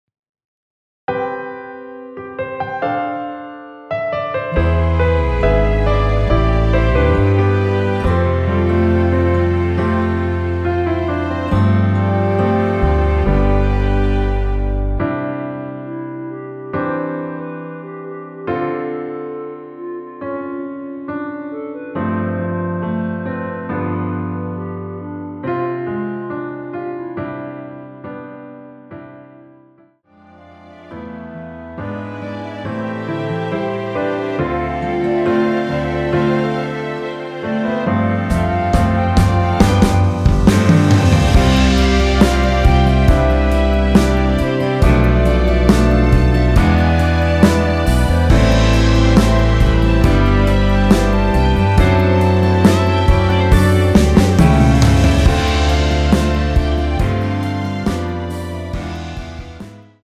원키(1절+후렴)으로 진행되는 멜로디 포함된 MR입니다.(본문의 가사와 미리듣기 확인)
노래방에서 노래를 부르실때 노래 부분에 가이드 멜로디가 따라 나와서
앞부분30초, 뒷부분30초씩 편집해서 올려 드리고 있습니다.
중간에 음이 끈어지고 다시 나오는 이유는